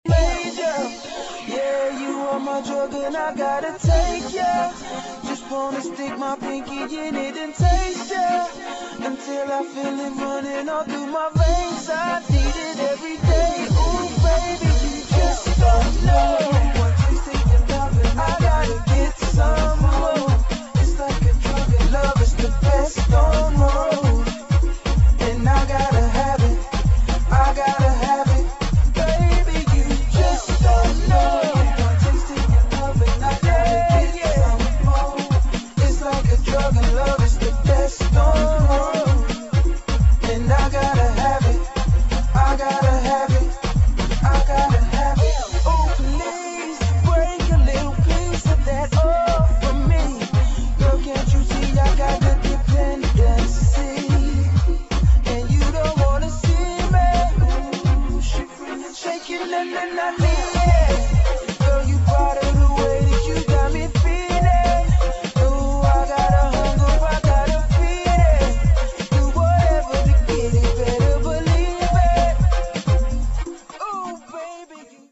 [ UK GARAGE / UK FUNKY ]